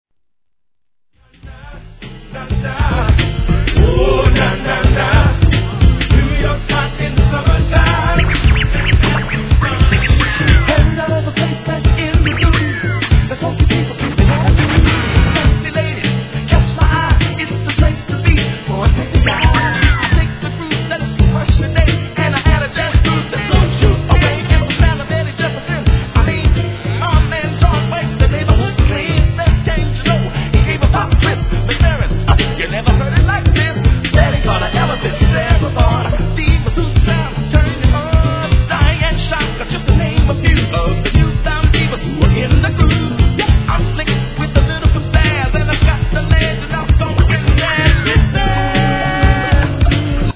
HIP HOP/R&B
COOLなJAZZYトラックに、コーラスWORK & RAPも実にCOOL！！